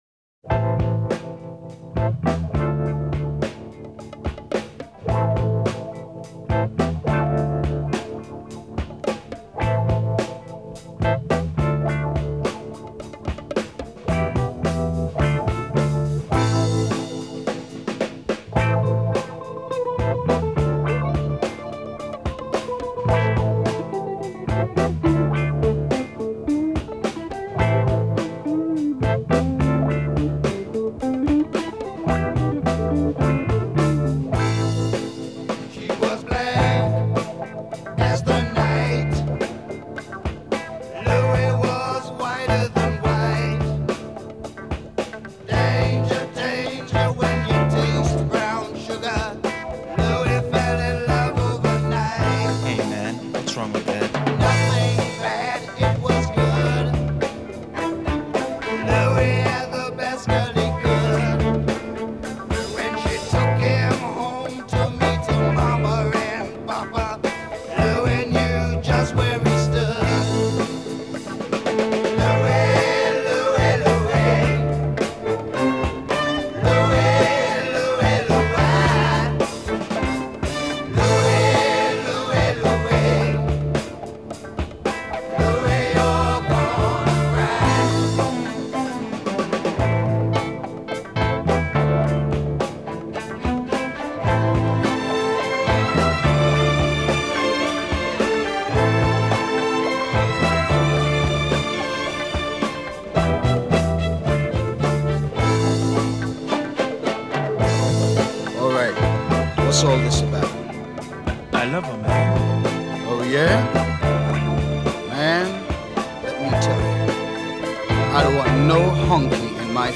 стили фанк и соул с элементами поп-рока и регги